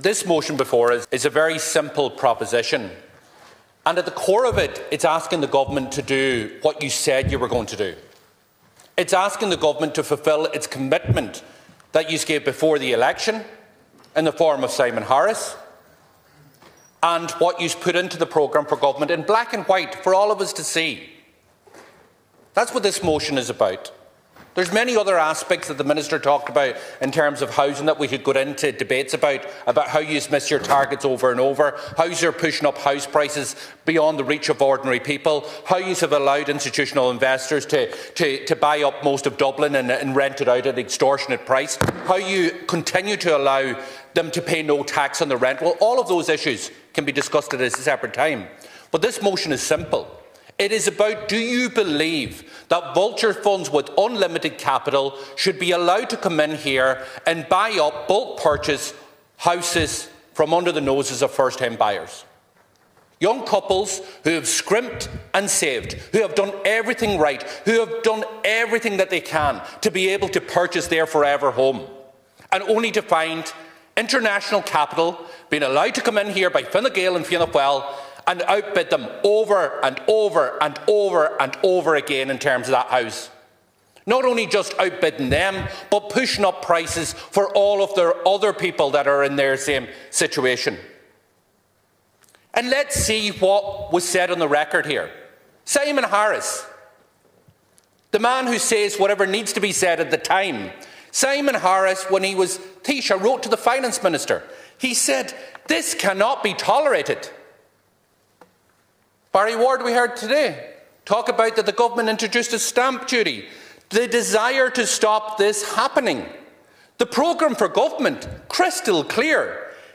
He was speaking as he introduced a motion in the Dáil last evening calling for the banning of investment funds buying family homes.